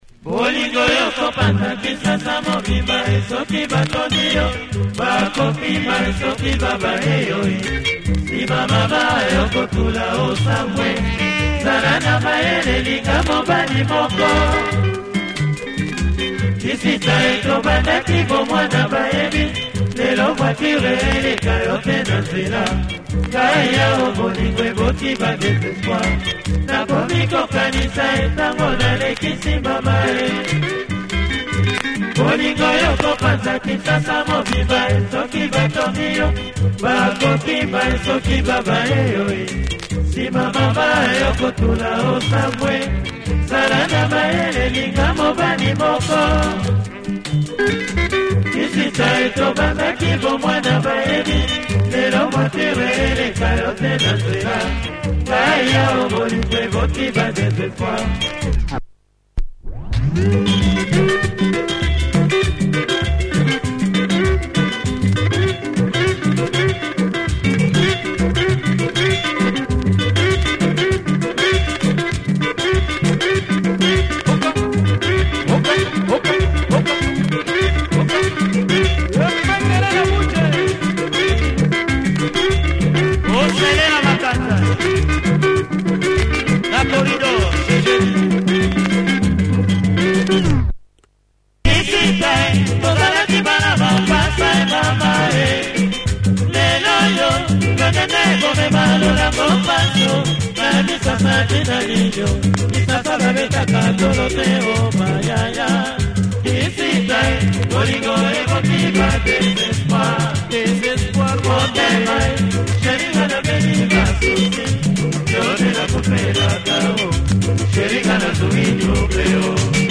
In rumba mode.